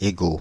Ääntäminen
Synonyymit moi Ääntäminen Paris: IPA: [e.go] France (Île-de-France): IPA: /e.go/ Tuntematon aksentti: IPA: /e.ɡo/ Haettu sana löytyi näillä lähdekielillä: ranska Käännös Substantiivit 1. ego Suku: m .